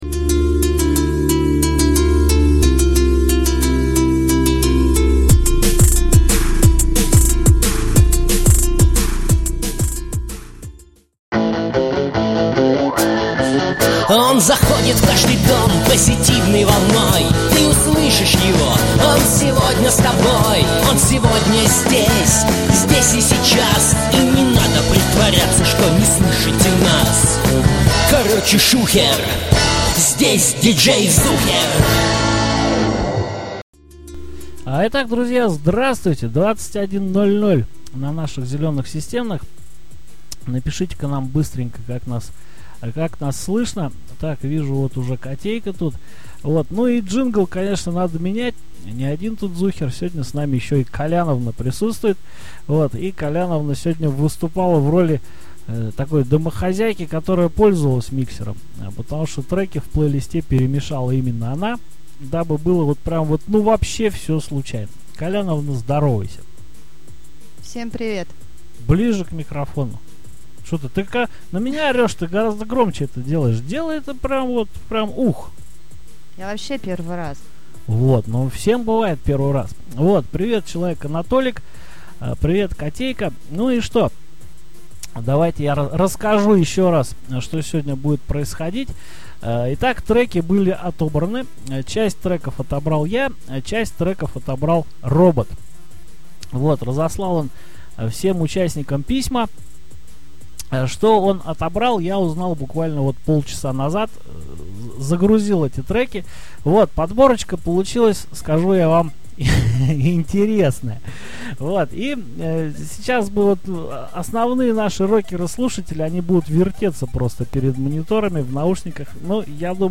Робот накидал целую кучу, разнообразных по стилю и качеству, треков.